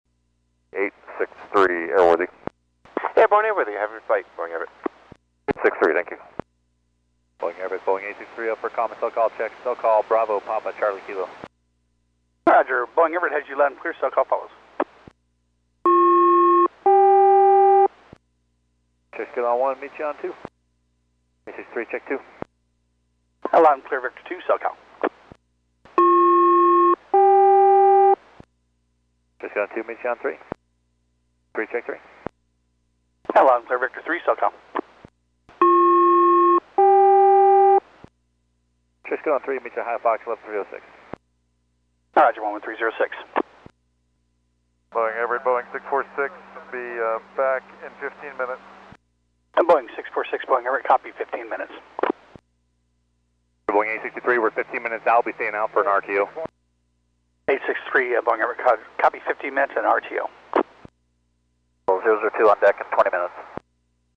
British Airways 777 G-STBO/N5014K B1 flight. Departure audio